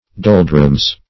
doldrums - definition of doldrums - synonyms, pronunciation, spelling from Free Dictionary
doldrums \dol"drums\ (d[o^]l"dr[u^]mz), n. pl. [Cf. Gael.